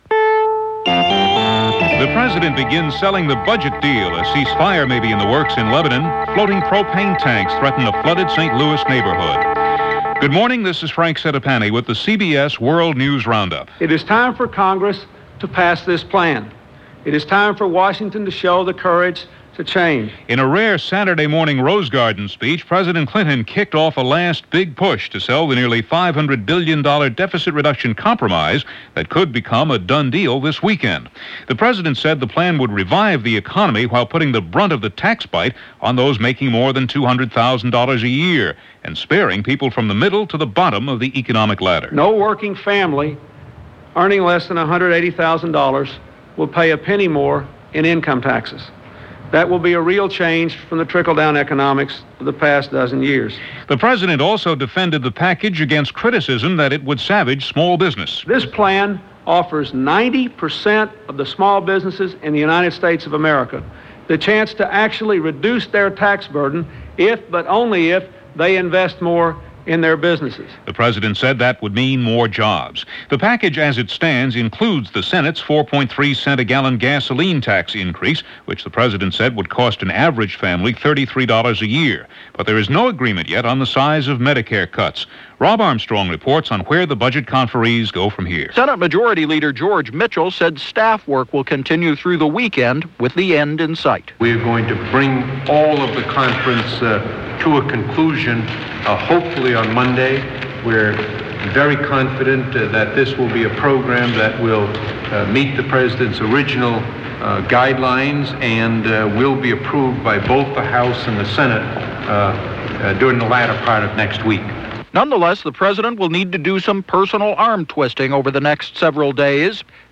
And that’s just a sample of what went on this last day of July 1993 from The CBS World News Roundup.